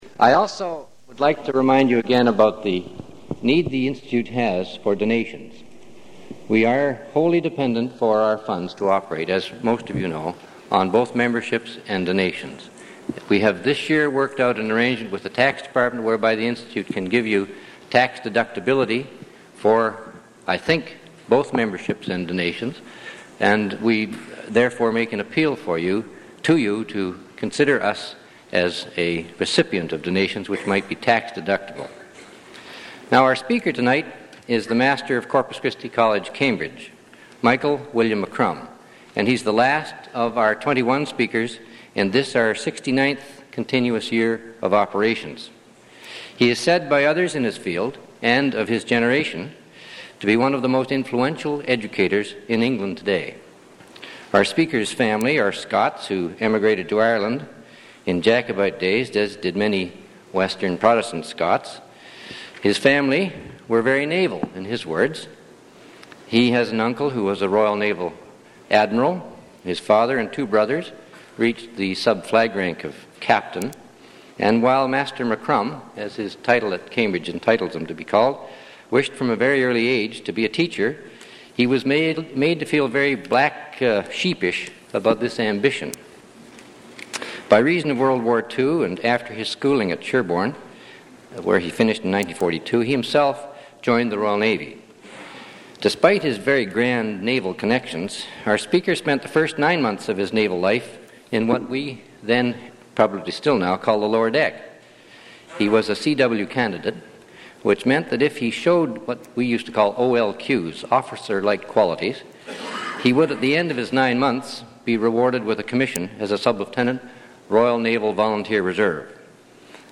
Original audio recording available in the University Archives (UBC AT 1318).